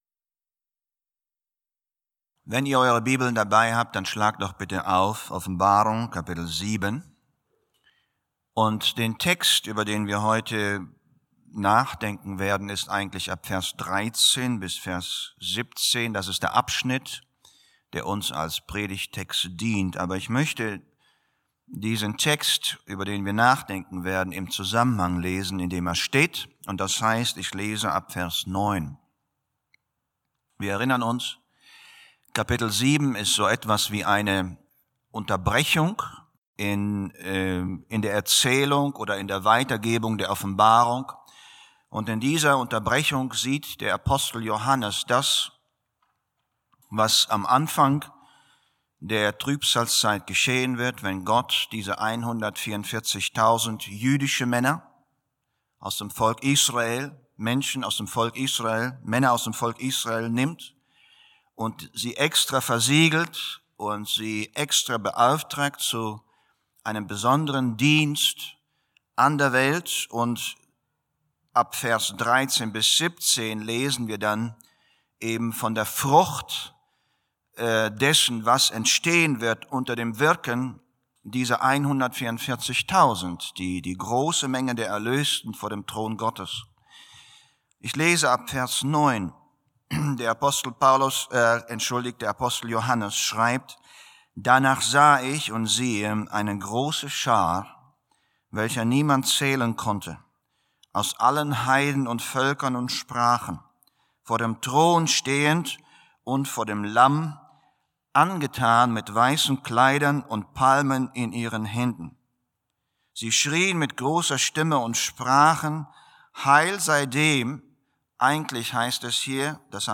german sermon